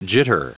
Prononciation du mot jitter en anglais (fichier audio)
Prononciation du mot : jitter